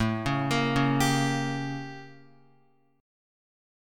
A7b9 chord